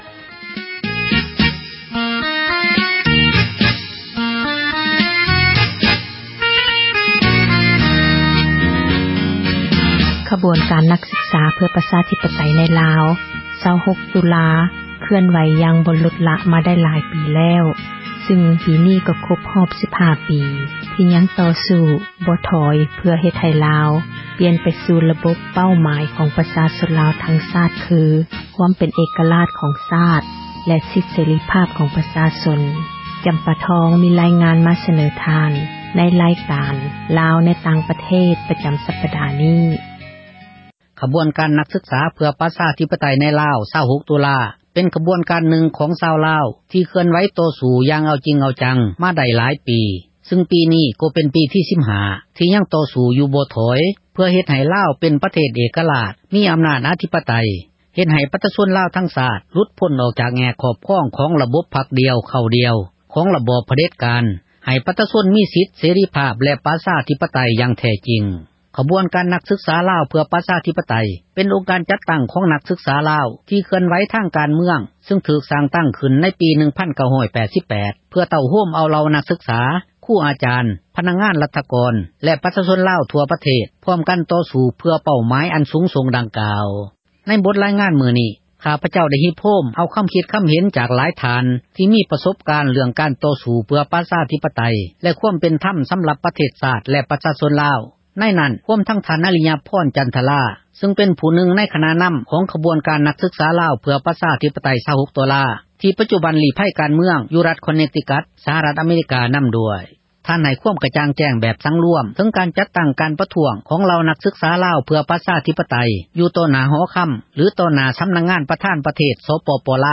ໃນບົດ ຣາຍງານ ນີ້ ຂ້າພະເຈົ້າ ໄດ້ຮິບໂຮມ ເອົາຄໍາຄິດ ຄໍາເຫັນ ຈາກຫຼາຍ ທ່ານ ທີ່ ມີປະສົບການ ເຣຶ່ອງ ການຕໍ່ສູ້ ເພື່ອ ປະຊາທິປະຕັຍ ແລະ ຄວາມເປັນທັມ ສໍາລັບ ປະເທສຊາດ ແລະ ປະຊາຊົນ ລາວ